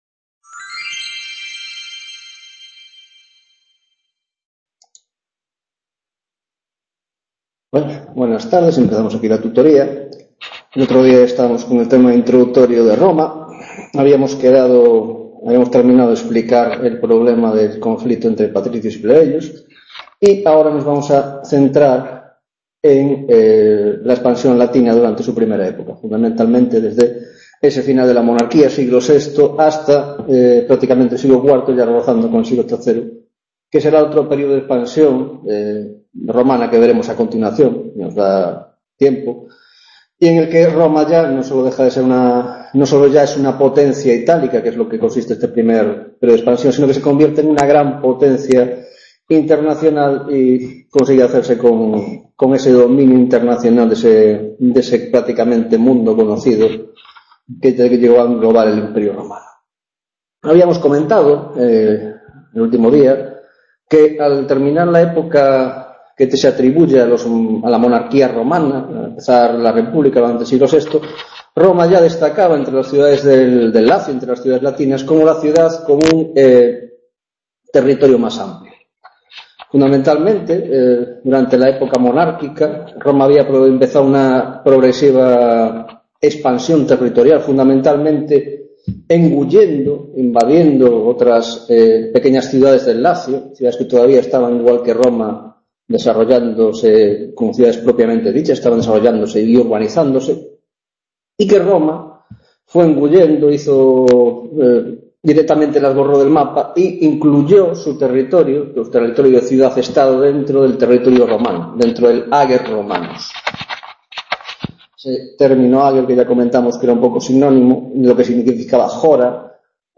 5ª Tutoria de Historia Antigua y Medieval (Grado de Filosofía): Roma: Expansión itálica y Guerras Púnicas